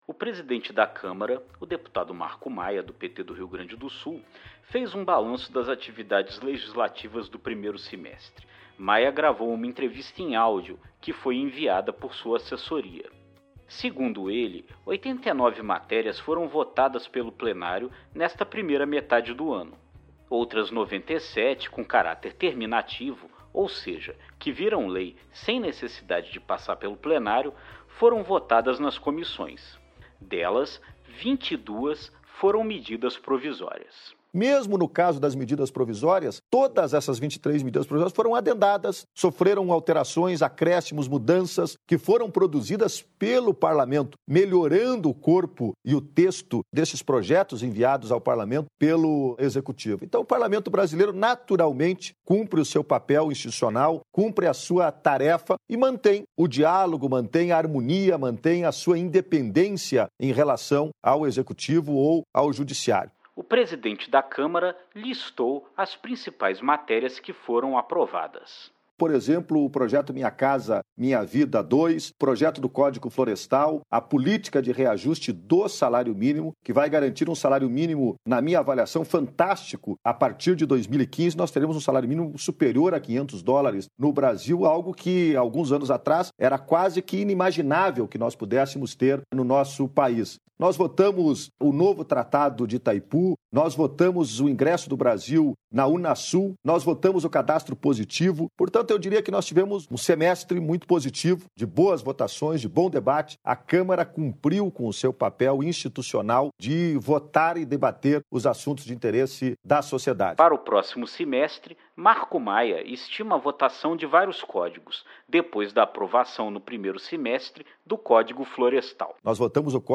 O balanço foi feito em áudio, pela assessoria do presidente da Câmara.